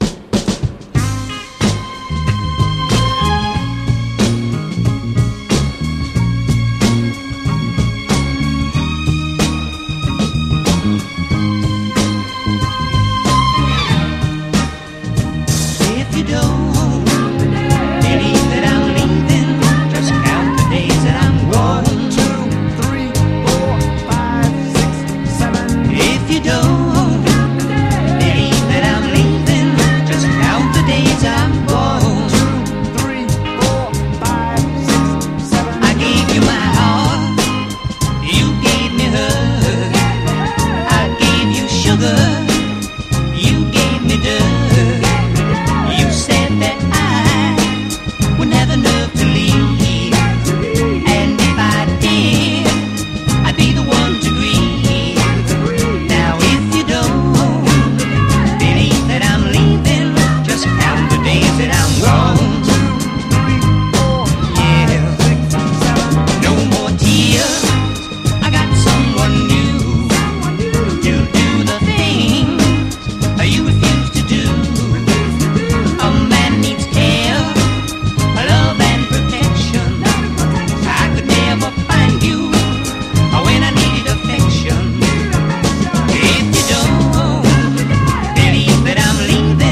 ROCK / SOFTROCK. / NORTHERN SOUL / SUNSHINE POP
ノーザン・ソウル～サンシャイン・ポップを縦断する隠れ傑作！